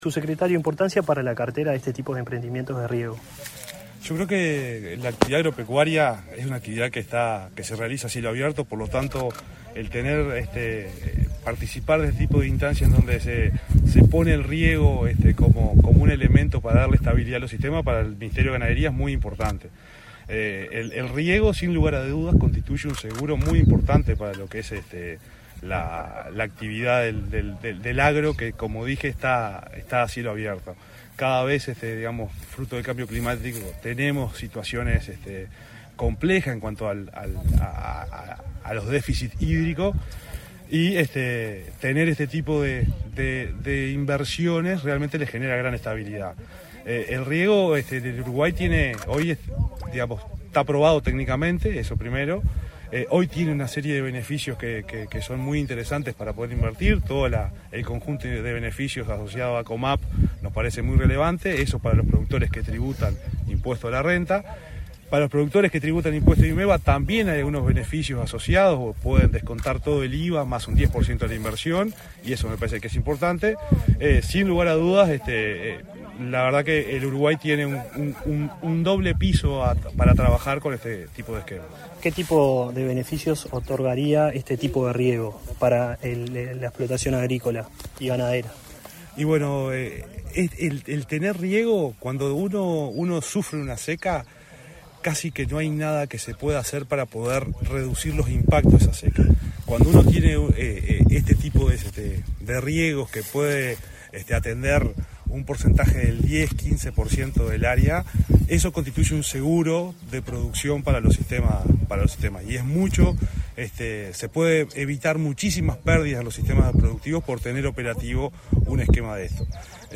Declaraciones del subsecretario de Ganadería, Agricultura y Pesca, Juan Ignacio Buffa
Declaraciones del subsecretario de Ganadería, Agricultura y Pesca, Juan Ignacio Buffa 19/11/2021 Compartir Facebook X Copiar enlace WhatsApp LinkedIn Tras participar en el lanzamiento del sistema de riego solar fotovoltáco en Solís Grande, en Maldonado, el subsecretario de Ganadería, Agricultura y Pesca, Juan Ignacio Buffa, efectuó declaraciones a Comunicación Presidencial.